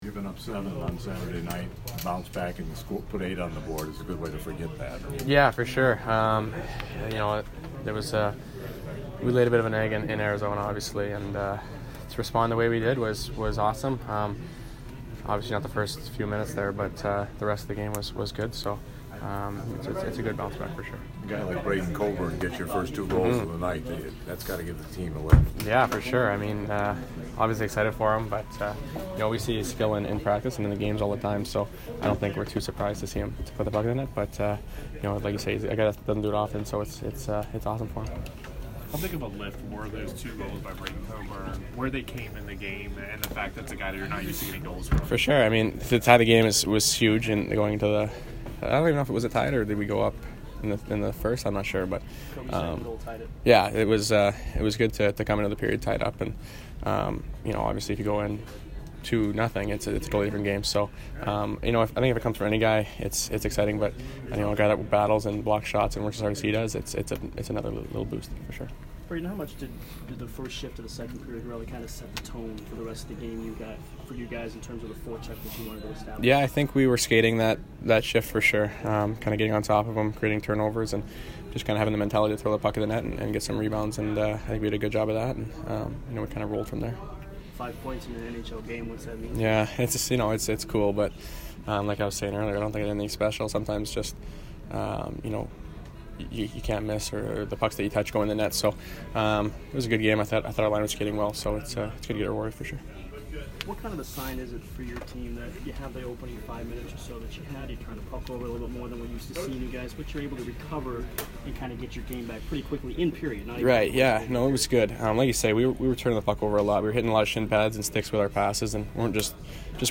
Brayden Point post-game 10/30